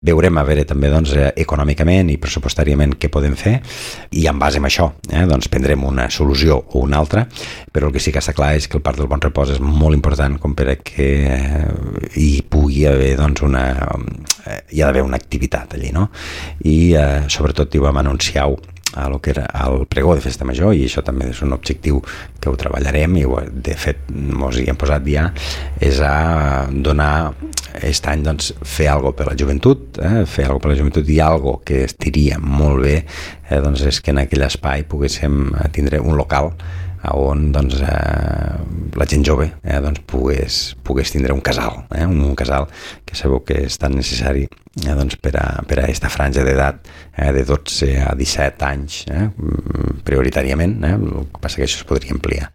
Jordi Gaseni és l’alcalde de l’Ametlla de Mar: